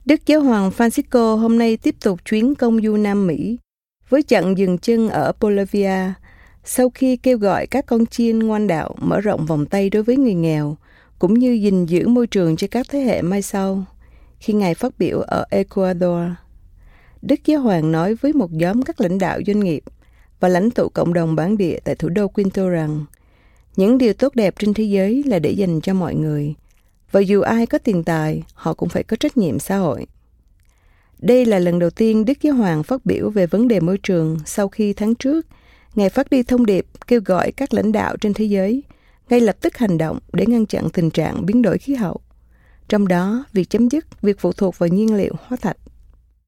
Voice Overs
VI AD EL 01 eLearning/Training Female Vietnamese